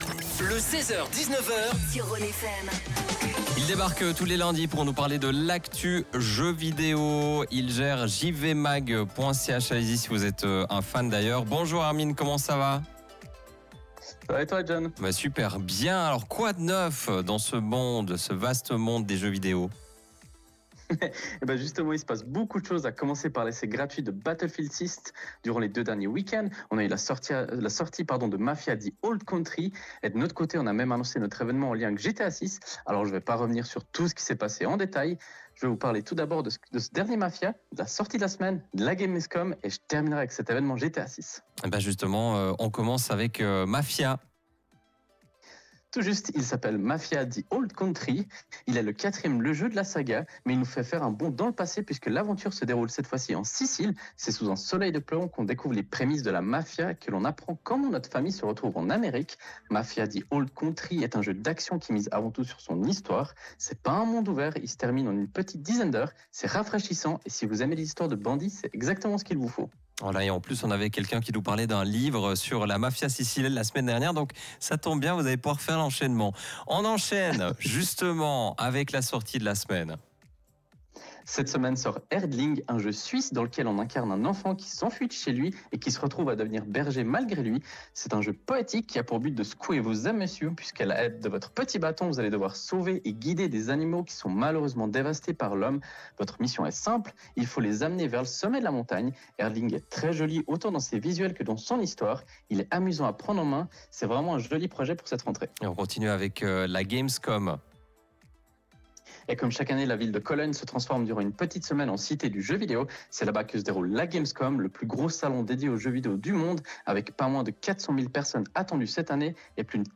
Comme tous les lundis, nous avons la chance de présenter notre chronique gaming sur la radio Rhône FM.
Vous pouvez réécouter le direct Rhône FM via le flux qui se trouve juste en haut de l’article.